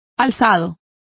Also find out how alzados is pronounced correctly.